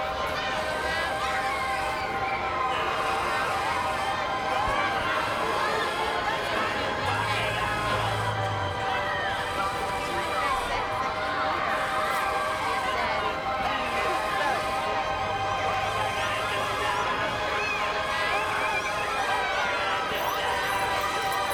D12 City Pad.wav